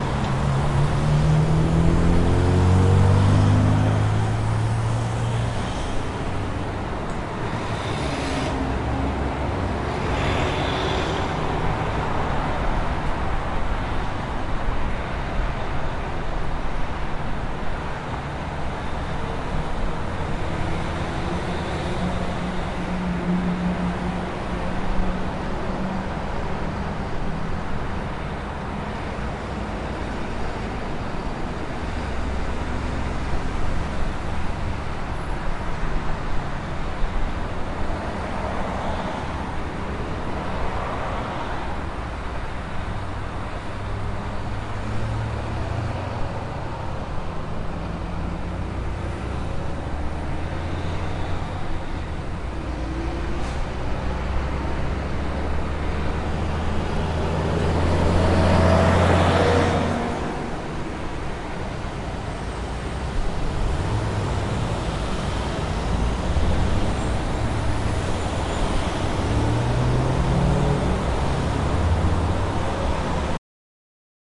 清晨的交通
描述：环形交叉路口的早高峰时段交通记录在Zoom H4nSP上的汽车旅馆窗口。
Tag: 公路 道路 交通 街道 汽车 卡车 现场录音